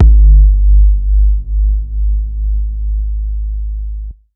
Kick SwaggedOut 7.wav